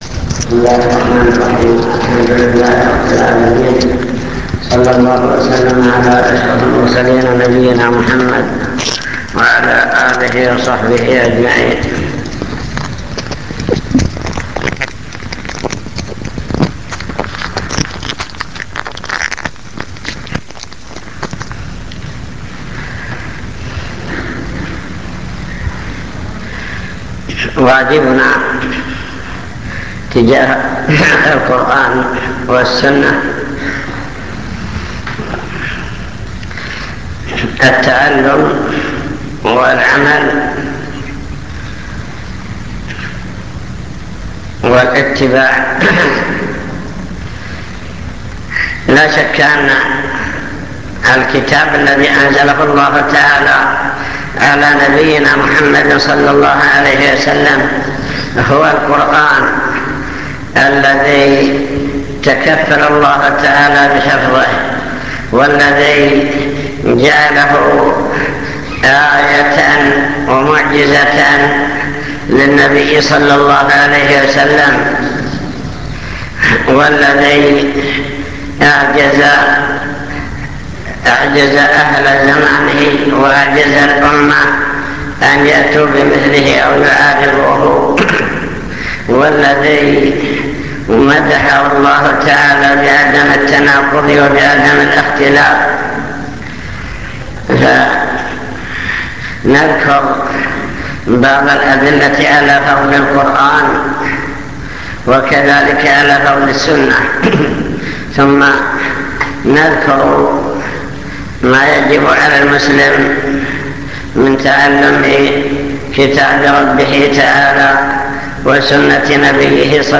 المكتبة الصوتية  تسجيلات - محاضرات ودروس  محاضرة عن القرآن والسنة